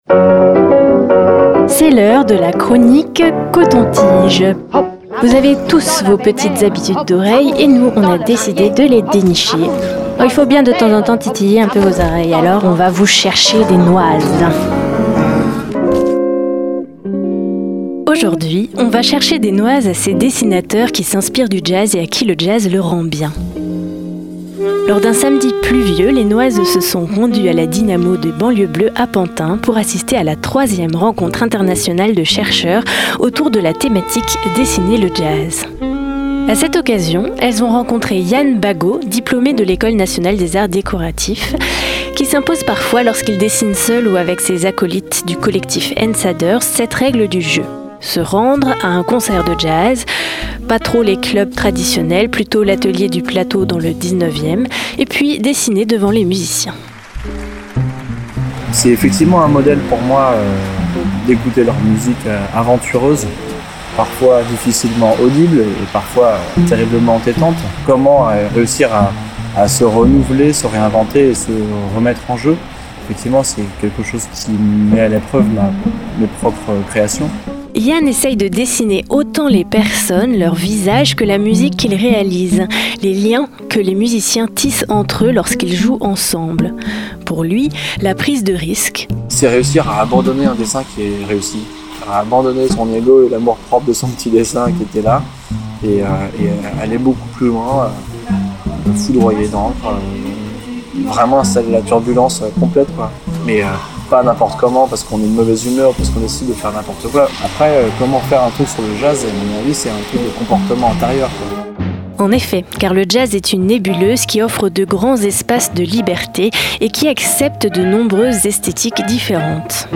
L'eau battait le toit vitré de la Dynamo des banlieues bleues.
Et puis un grand train passait au passage prendre tout un tas de standards, qui s'embarquaient pour le grand leitmotiv de la locomotive : Tchou! Tchou!